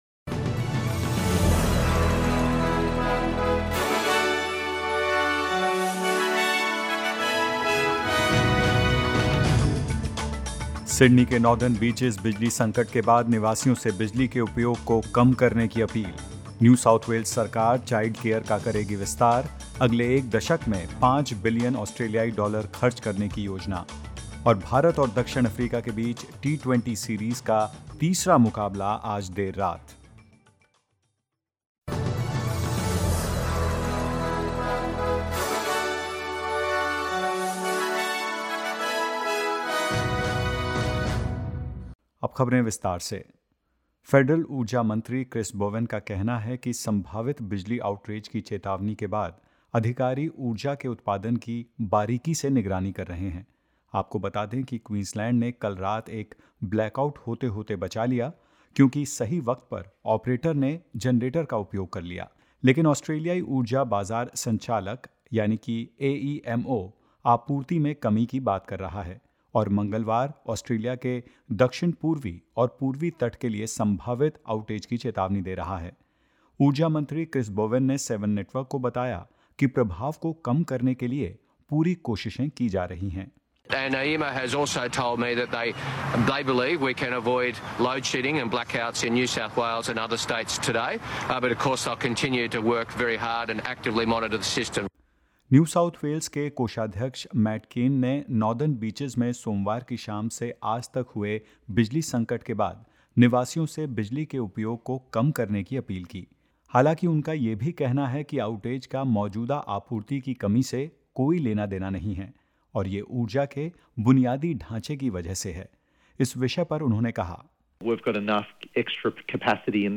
In this latest SBS Hindi bulletin: NSW to get a $5b boost in the Childcare sector; Third T-20 cricket match between India and South Africa to be played tonight and more